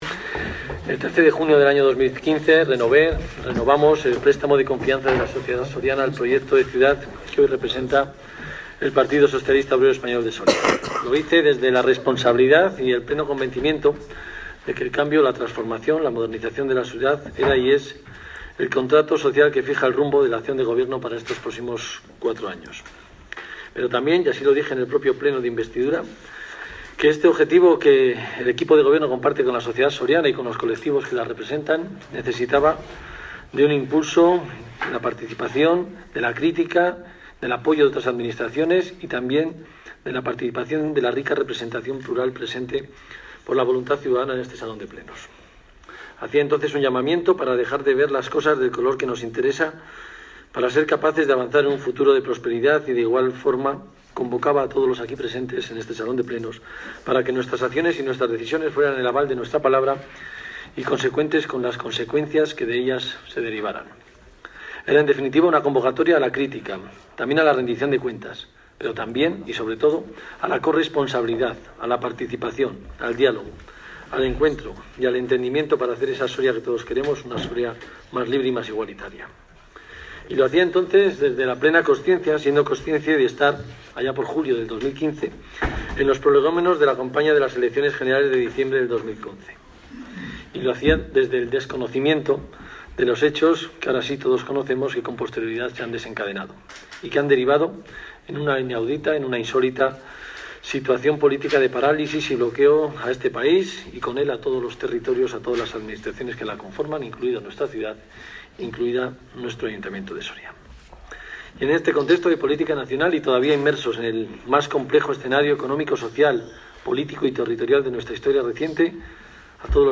Audio Discurso Debate Municipio